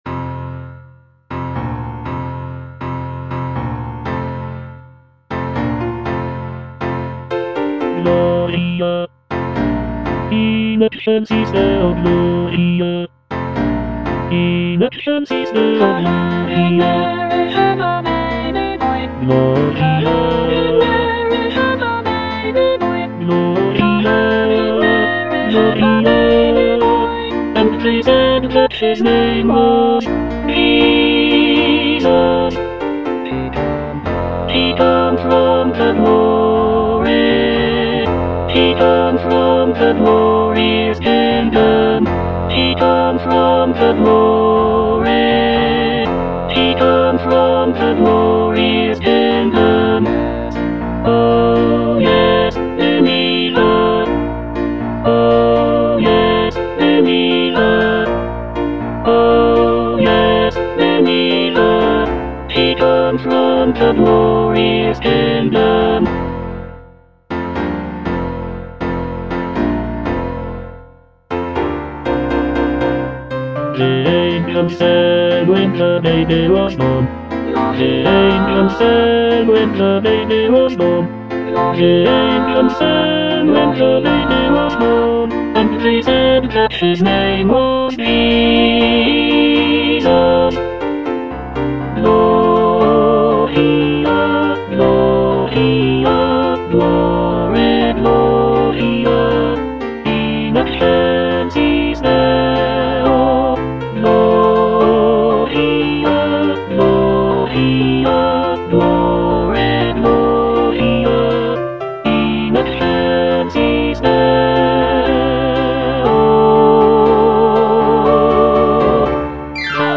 Tenor (Emphasised voice and other voices)
" set to a lively calypso rhythm.